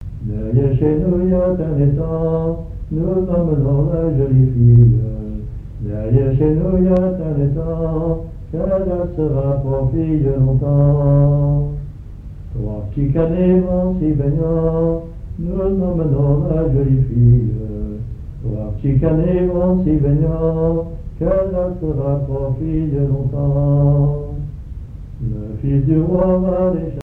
Airs à danser aux violons et deux chansons
Pièce musicale inédite